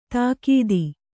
♪ tākīdi